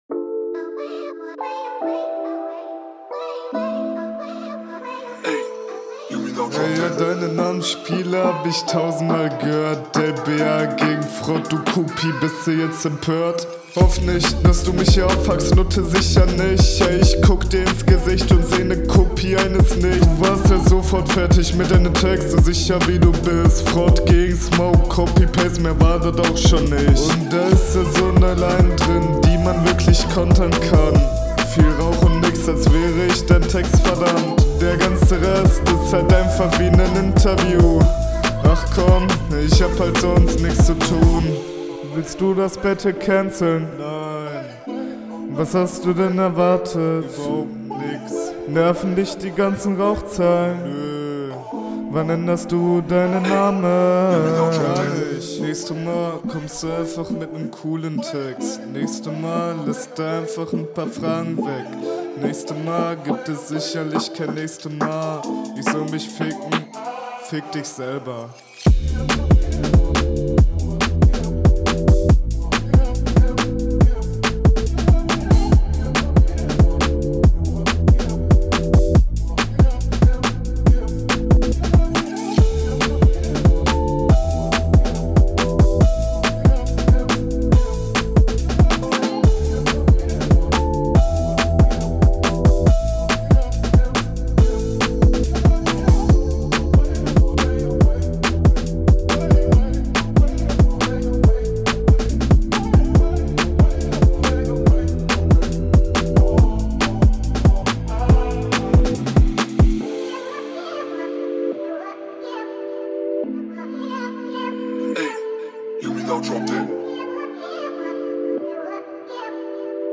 Mische n bisschen Dumpf und der Hall stört mich ein wenig.